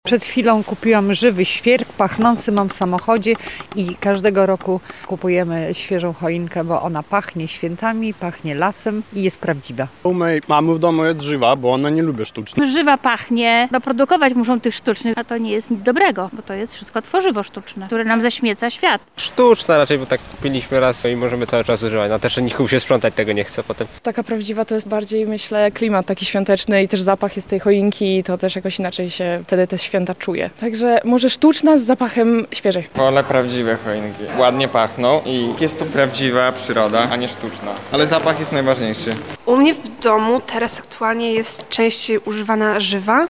(SONDA) Choinka sztuczna czy żywa?
Zapytaliśmy mieszkańców Lublina, co sądzą na ten temat: